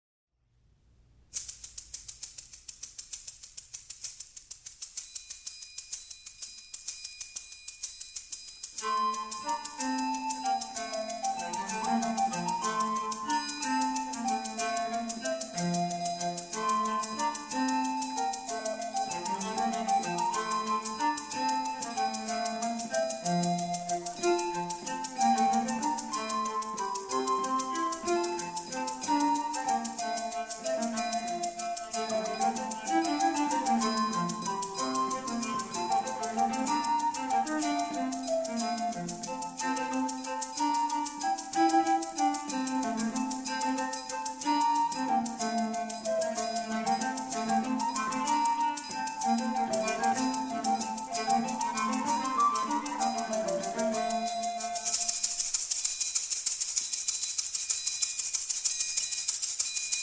A Renaissance Band
sackbut, recorders
cornetto, gemshorns, recorders
percussion, viol, recorders
vielle, viols